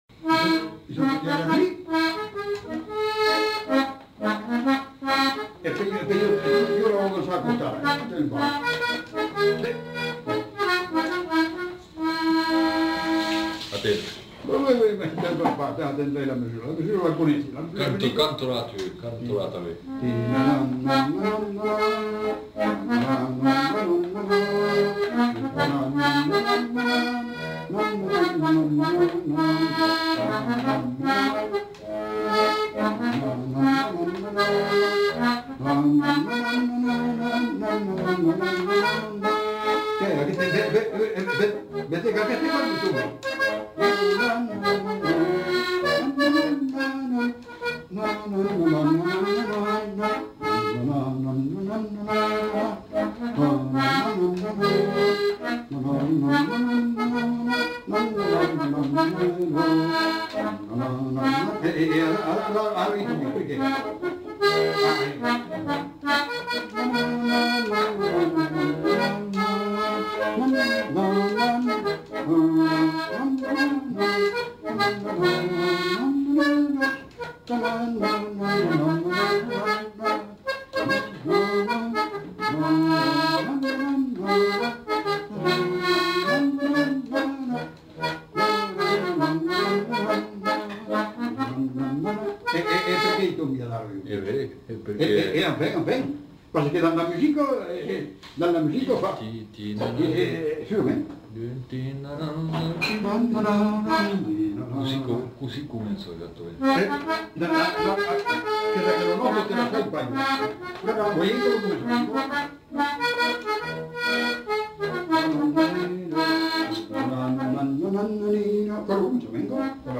Mazurka (fredonné)
Aire culturelle : Haut-Agenais
Type de voix : voix d'homme Production du son : fredonné Instrument de musique : accordéon diatonique
Danse : mazurka
L'informateur fredonne, et l'enquêteur joue la mélodie à l'accordéon diatonique. Ils cherchent à caler la musique sur la danse.